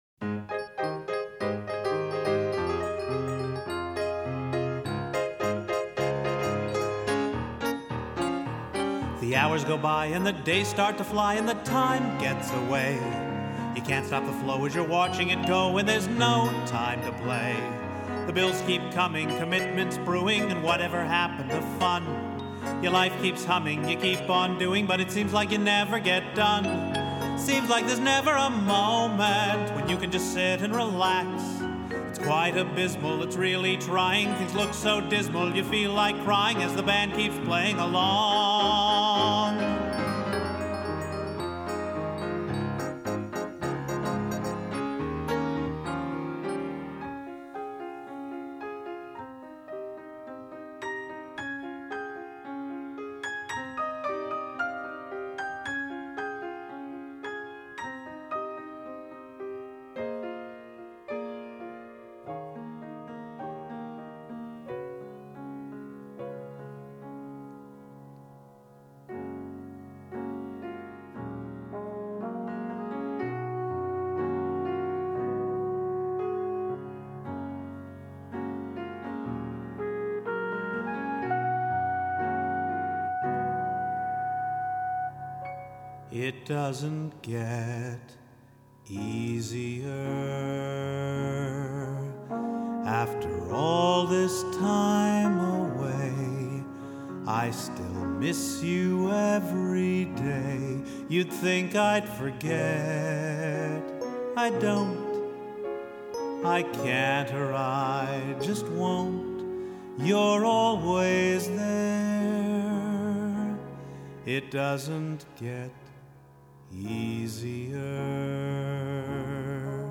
This number then covers the next fifteen years of their lives – it was really fun to write – there are sections where you’ll only hear underscore – all those have monologues that go there – but the song has an interesting rhyme scheme – easy to do for one verse, but successively harder to do for the subsequent ones.